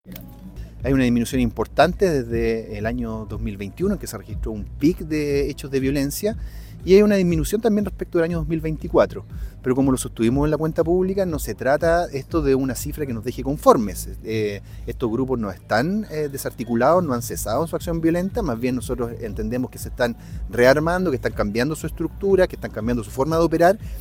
Estas afirmaciones fueron realizadas por el fiscal regional de La Araucanía, Roberto Garrido, en su Cuenta Pública del año 2025 que entregó a la comunidad en el Cerro Ñielol de Temuco, donde aseveró que, si bien los delitos vinculados a la llamada violencia rural han tenido una baja importante, van a seguir trabajando porque aún hay grupos radicales que no están desarticulados y siguen operando en la zona.